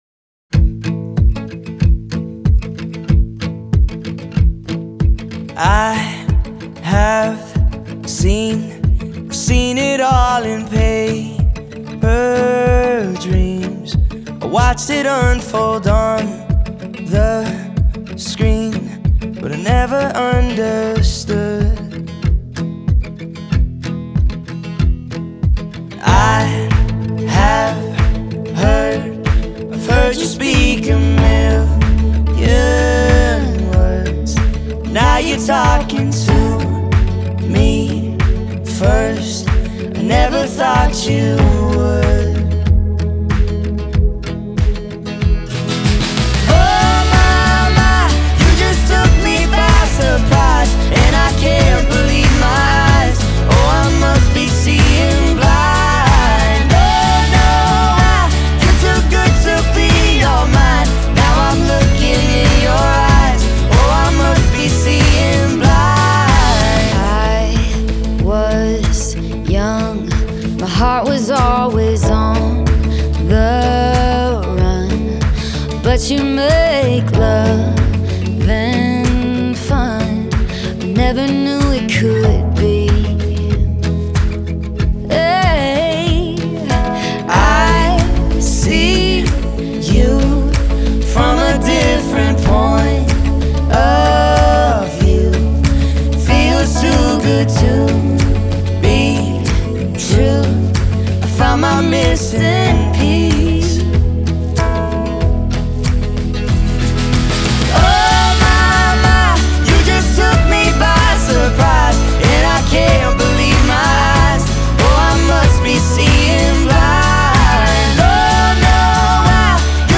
Genres: Country, Country Pop🎸 Released At